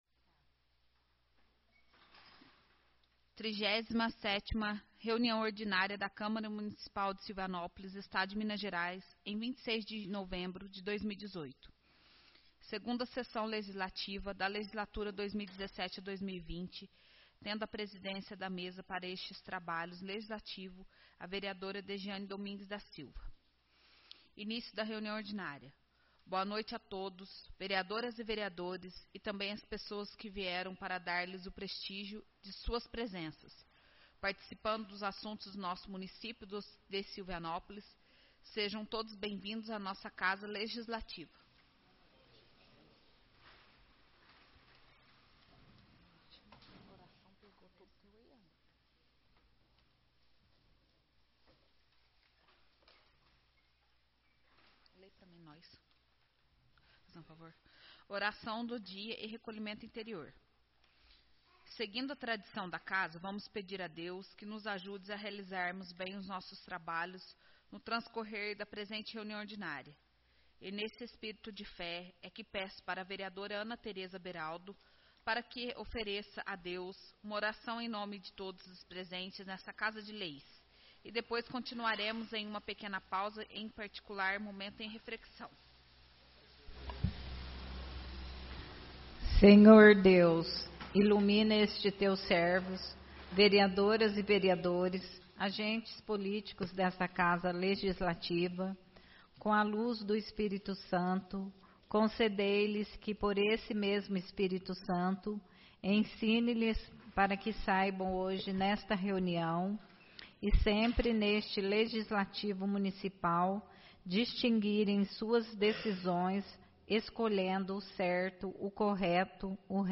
Áudio da 37ª Reunião Ordinária